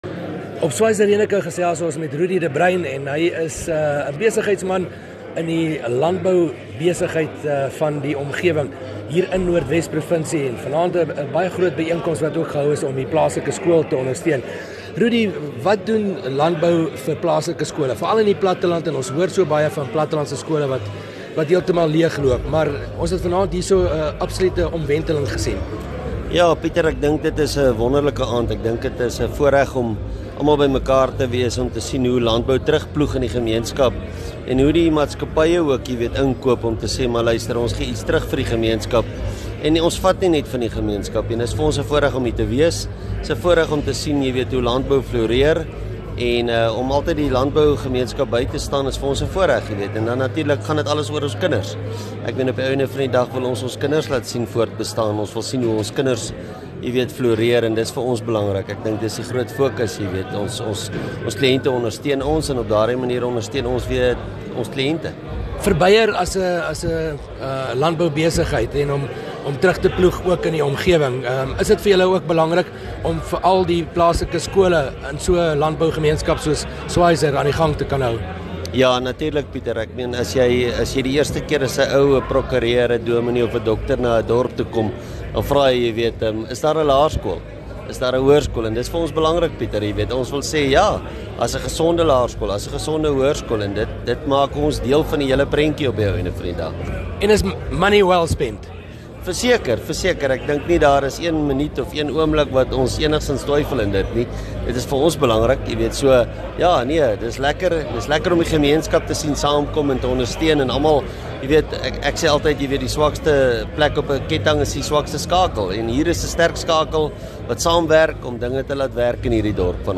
'n landbou byeenkoms in Schweizer-Reneke in Noordwes bygewoon en gesels met een van die verteenwoordigers van 'n landbou maatskappy daar.